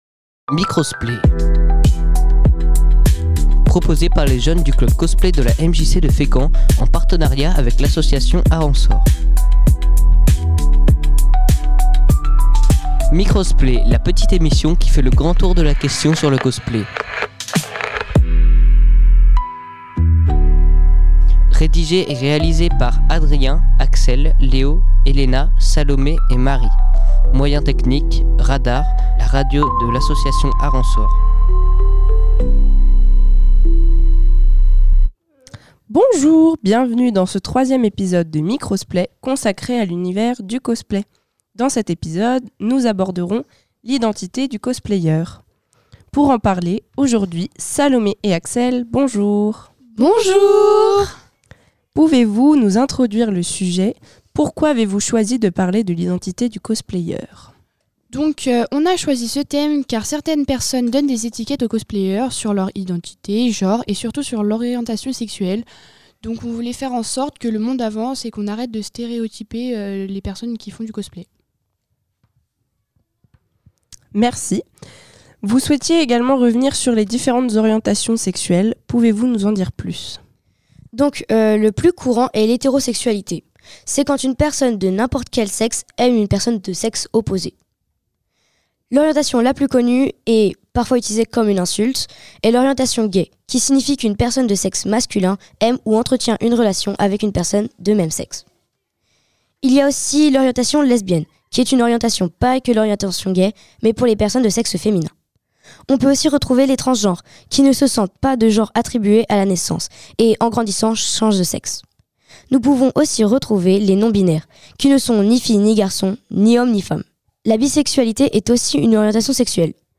En partenariat avec la MJC, les jeunes cosplayers désireux de partager leur passion nous donnent rendez vous tous les mercredis midi du mois de juin 2025 pour une émission entièrement réalisée par leur soin avec les moyens techniques de RADAR